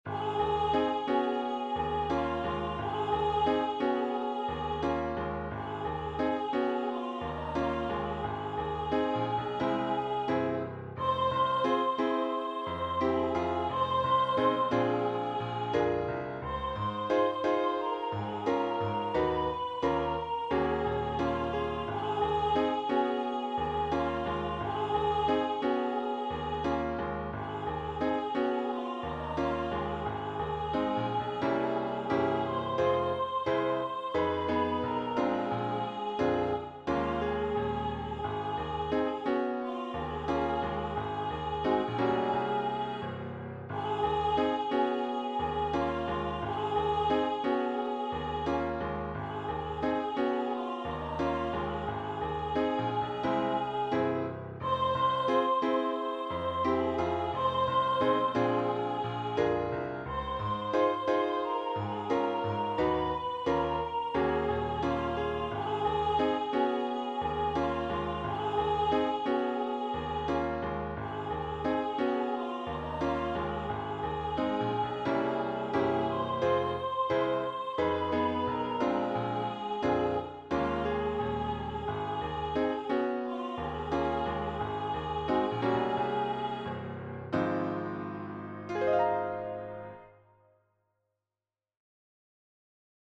African American spiritual